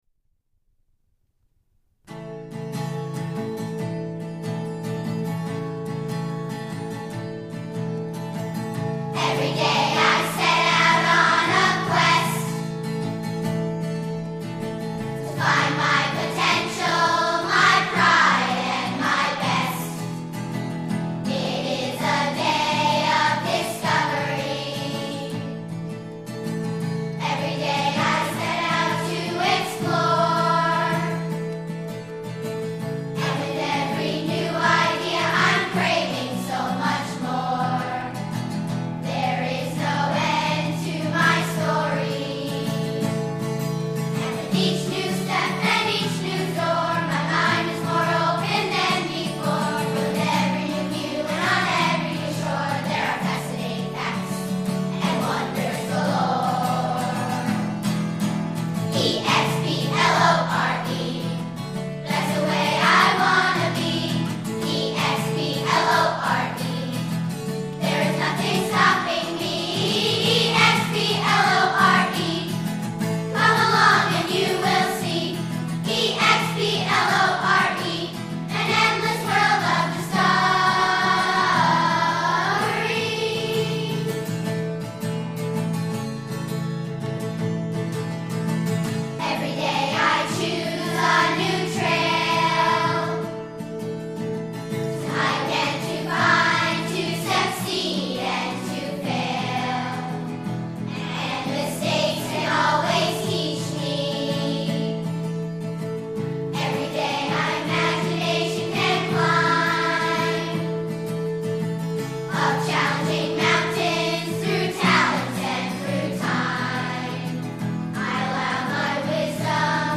Children's Vocal Group